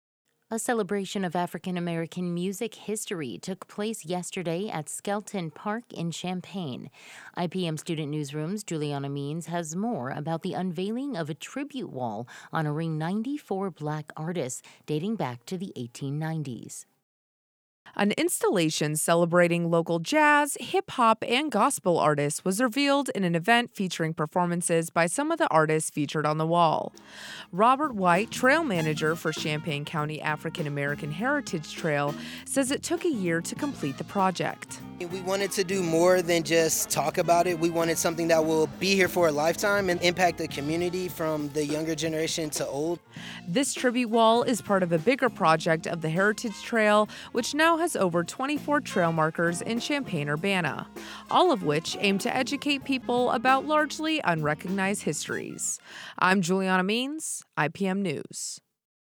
plays trumpet after the unveiling of the African American Musician Tribute Wall at Skelton Park in Champaign on Sept. 28
CHAMPAIGN — Jazz, gospel and hip hop filled the air as a tribute wall dedicated to the Black musicians of Champaign was revealed Sunday at Skelton Park in Champaign.
The event featured live music from the band Afro D & Global Soundwaves, which specializes in hip hop, jazz and funk — all genres celebrated in the wall.
Crowds gathered, cheering and dancing along to the music.